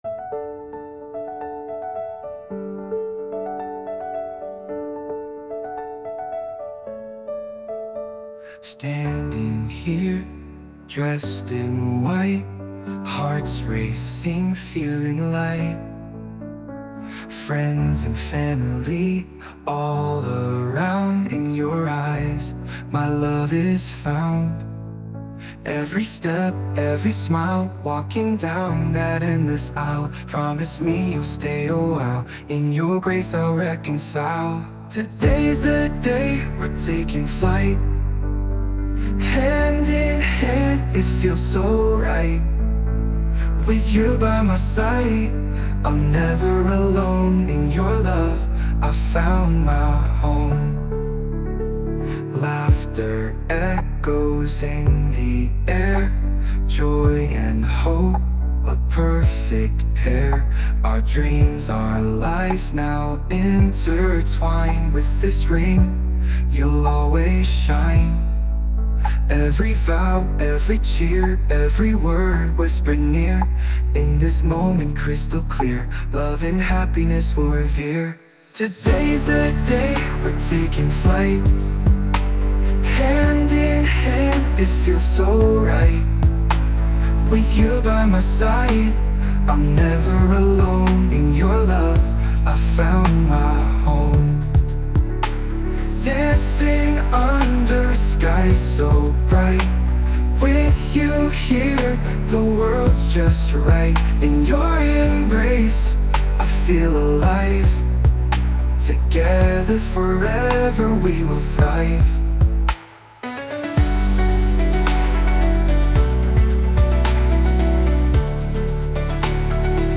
洋楽男性ボーカル著作権フリーBGM ボーカル
男性ボーカル（洋楽・英語）曲です。